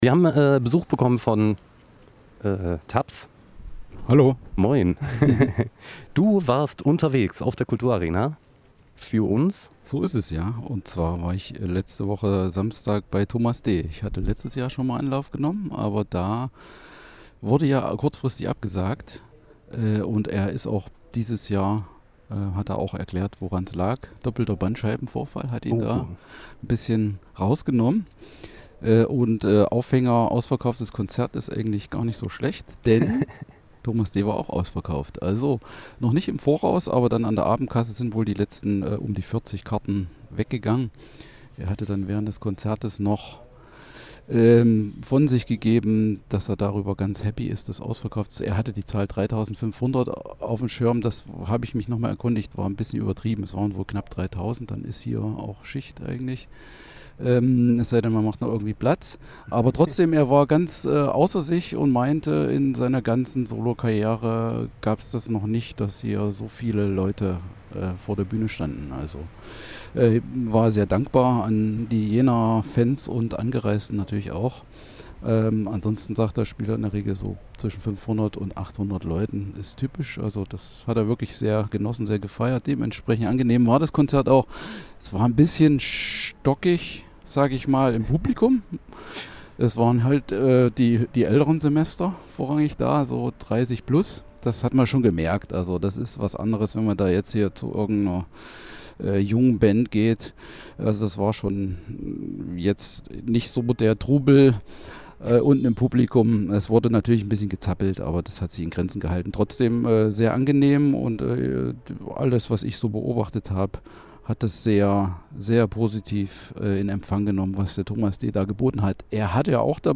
RadioArena Konzertrezension Tomas D